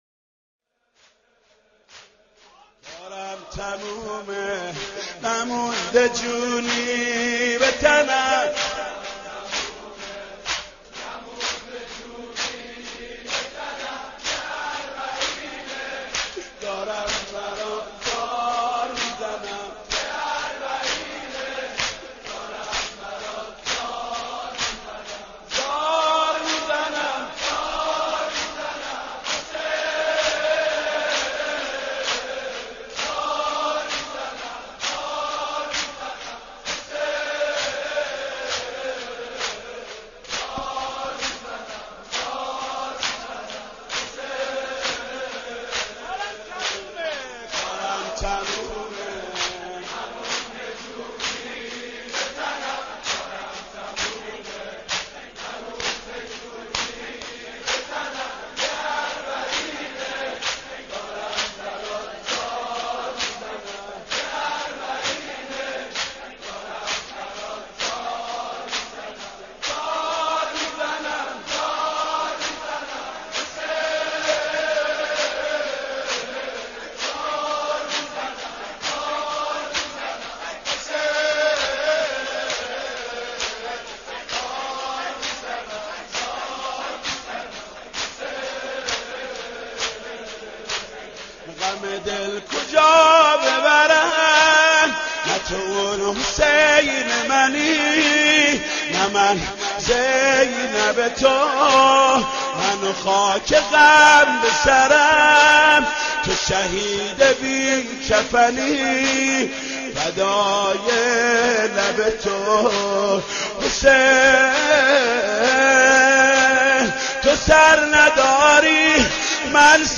صوت/ مداحی و سینه زنی با نوای «محمود کریمی»
مداحی و سینه زنی با نوای حاج محمود کریمی به مناسبت «اربعین حسینی» برای استفاده علاقمندان قابل بهره برداری است.
برچسب ها: حاج محمود کریمی ، اربعین ، سینه زنی ، مداحی